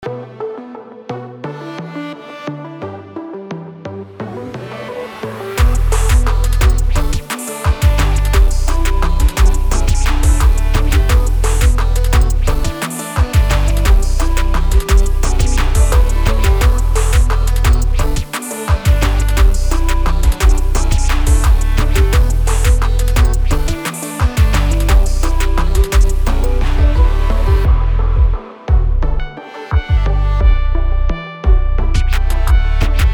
BPM: 174
Key: D Major